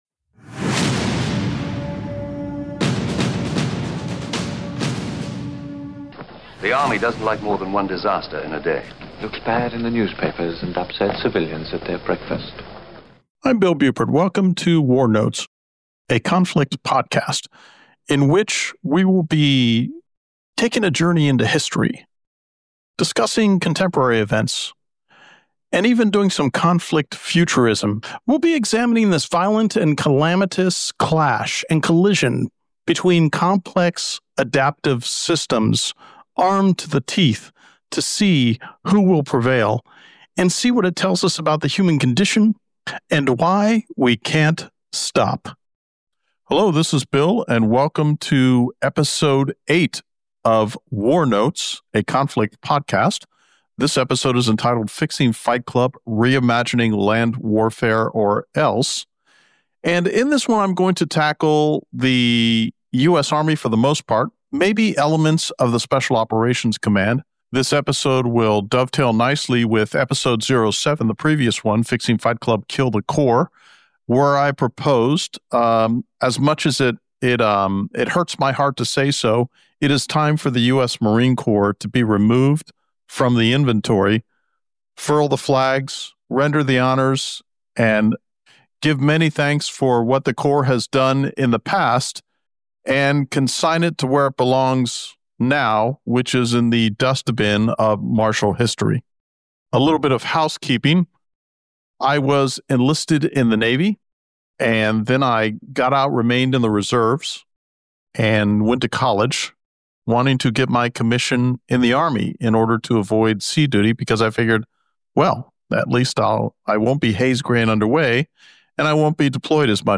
***Just discovered there was an audio synchronization problem that truncated from 1:11 to 0:50; it is now fixed.***